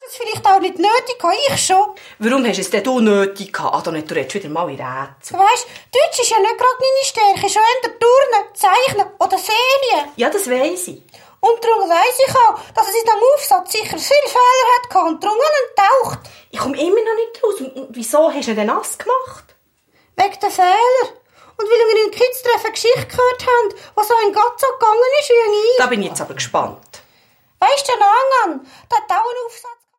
Hörspiel - Album